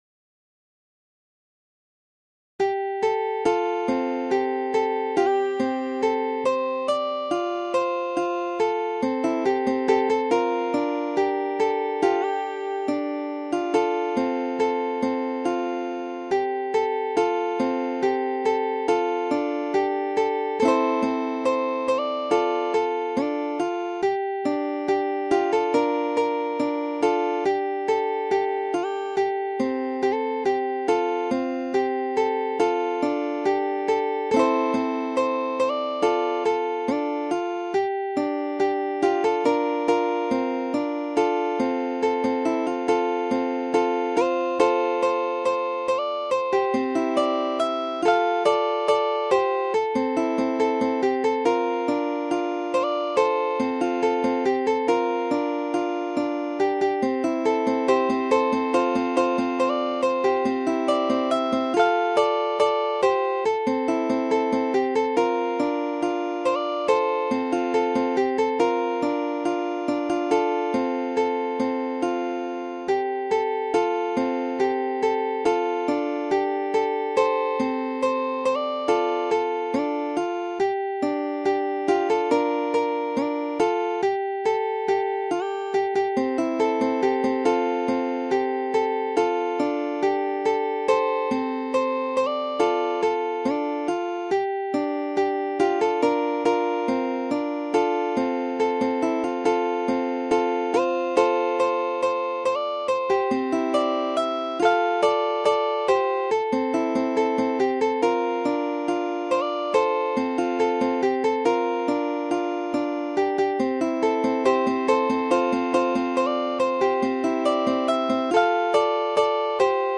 Ukulele Fingerstyle Solo Tab 乌克丽丽 指弹 独奏 谱